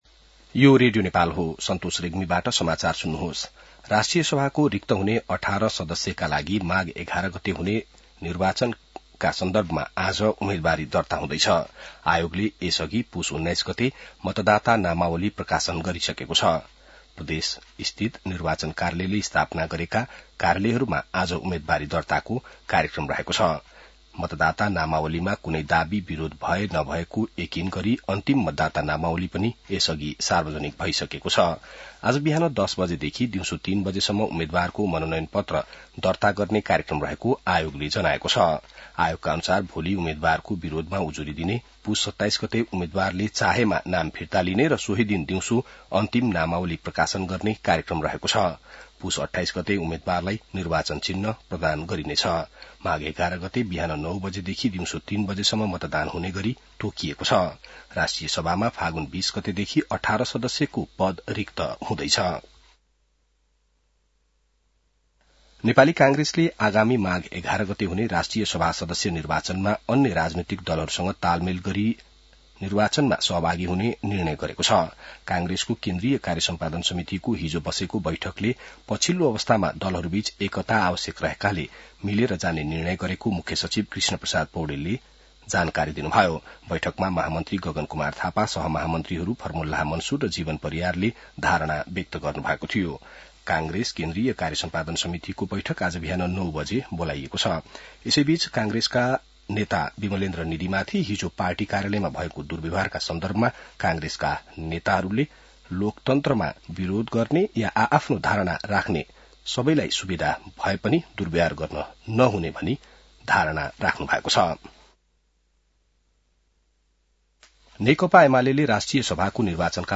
An online outlet of Nepal's national radio broadcaster
बिहान ६ बजेको नेपाली समाचार : २३ पुष , २०८२